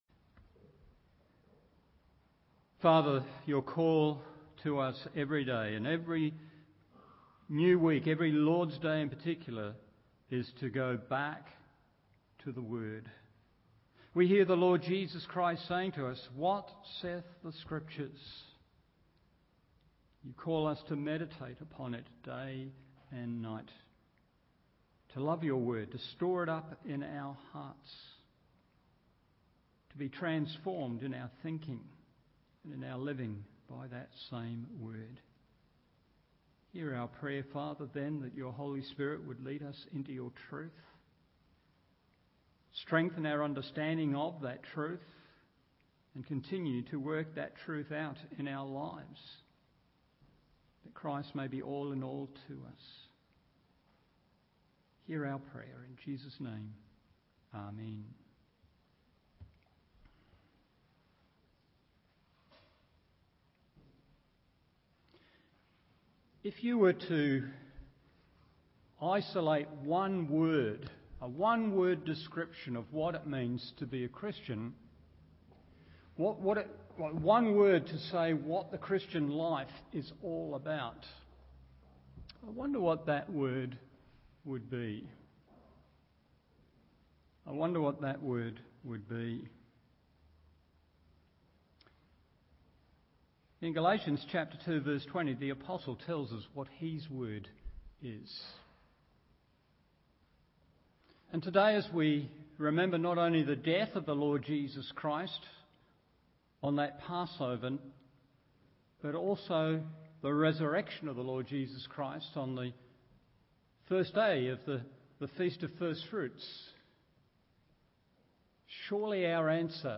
Evening Service Galatians 2:20 1. My Life in Christ 2. My Life by Christ 3. My Life for Christ…